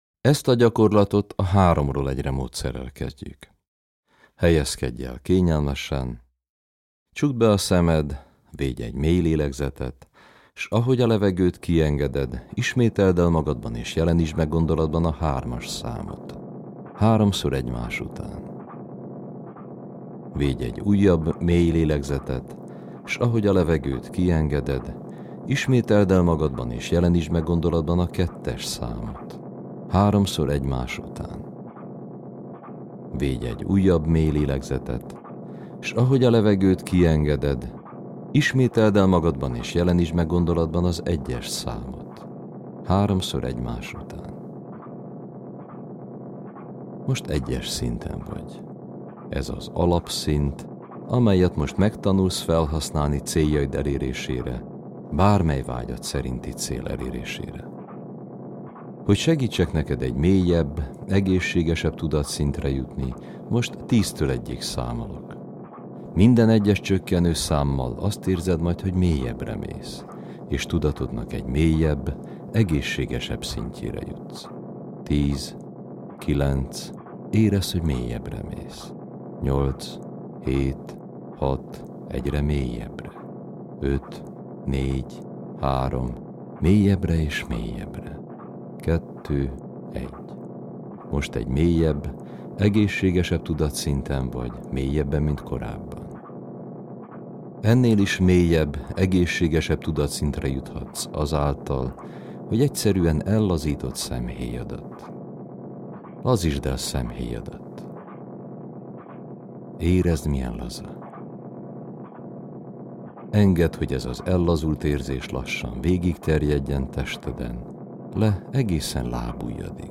Agykontroll Alapgyakorlat (alfahanggal, 23 perc, YouTube) Reggeli medit�ci� (zen�vel, 14 perc, YouTube) �nkorl�toz� hitek megv�ltoztat�sa (alfahanggal, 15 perc, mp3) Esti medit�ci� (zen�vel, 15 perc, YouTube)